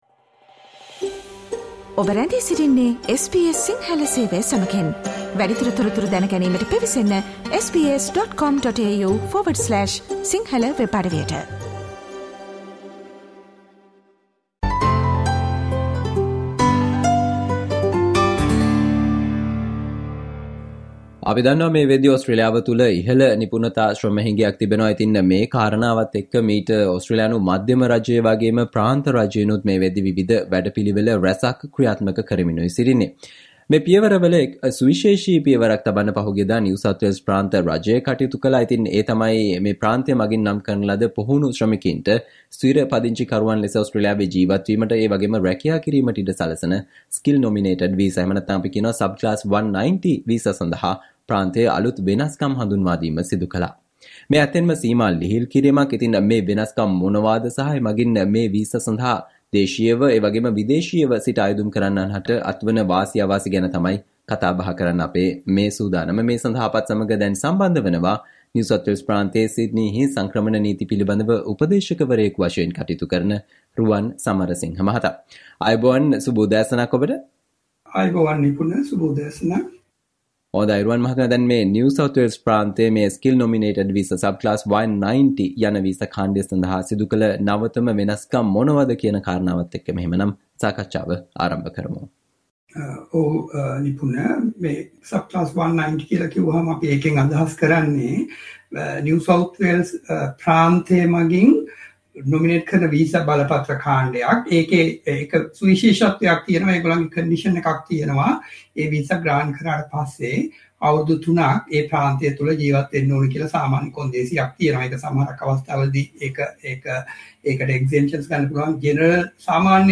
SBS Sinhala discussion on Important information for you about the latest changes made by NSW to the Skilled Nominated visa - 190